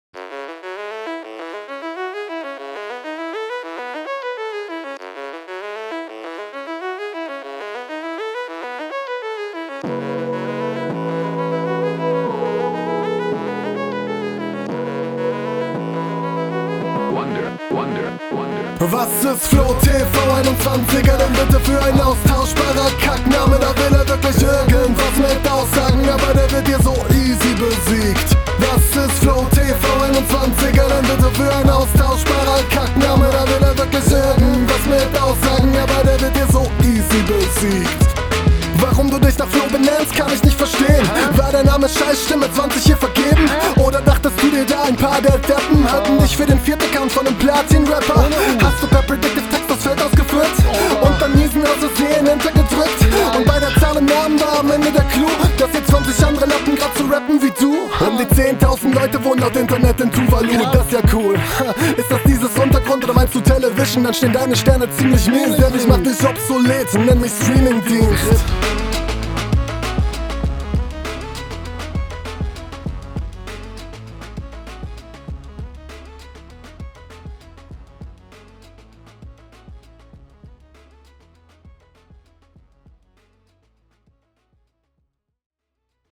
Insgesamt sehr sicher gerappt.
Beat ist sehr cool, gefällt mir vom Sound und Flow generell sehr gut, macht Spaß.